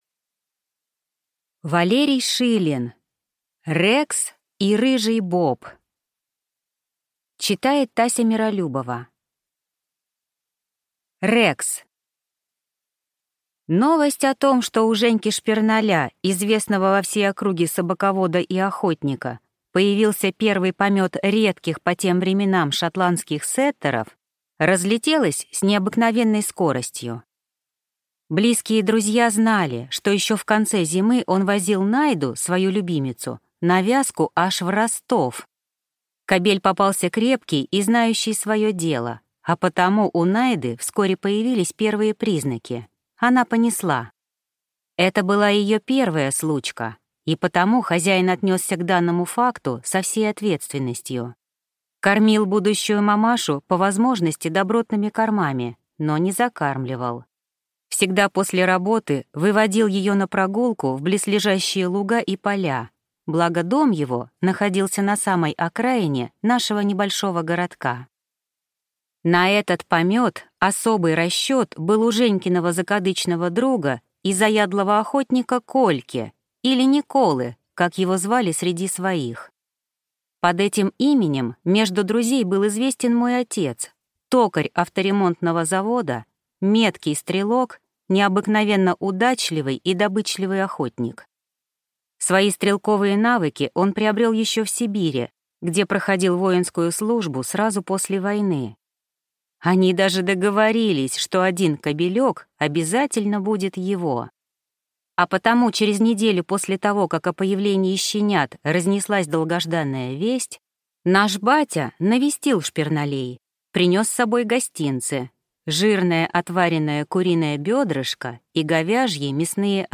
Аудиокнига Рекс и Рыжий Боб | Библиотека аудиокниг
Прослушать и бесплатно скачать фрагмент аудиокниги